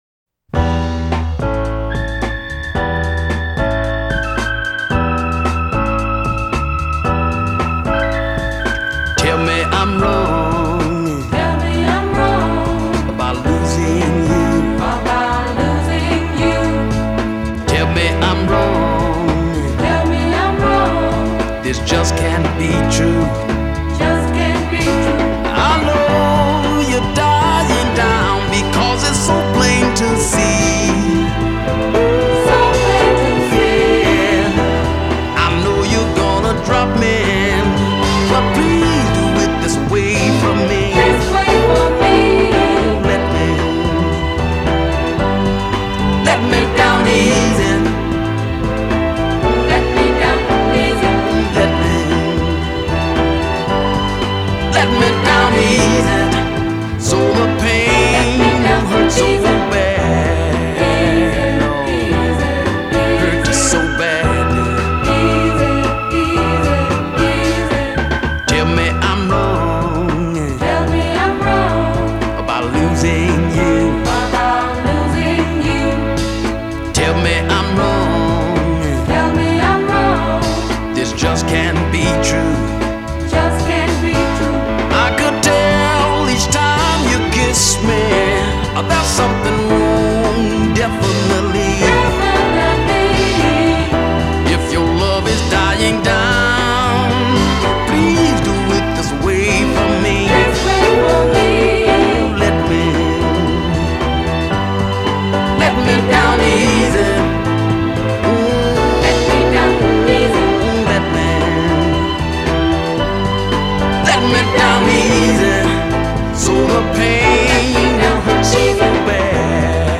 Posted by on December 2, 2014 in Soul/R&B and tagged , , .